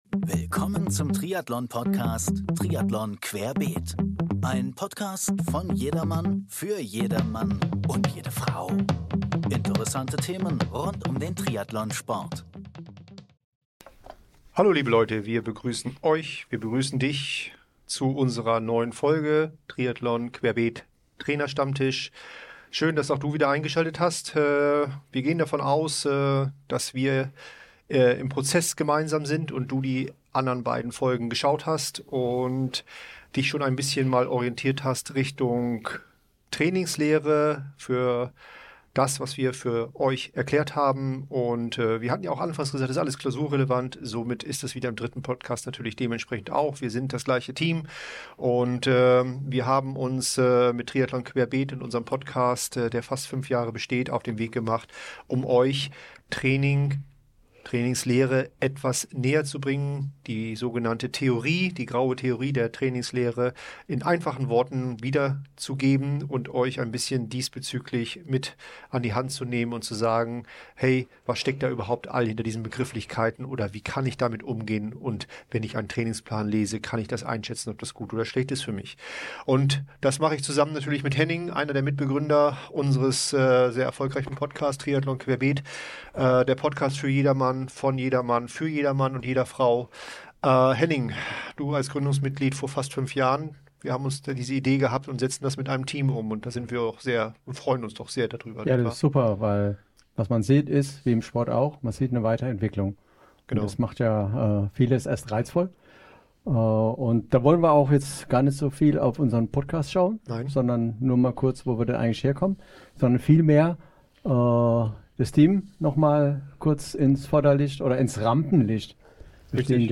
Gemeinsam diskutieren die vier Trainer Stärken, Schwächen und typische Anwendungsbereiche von Methoden wie dem FTP-Test, Conconi-Test, CSS-Test(Schwimmen), Laktatmessung oder der Spirometrie.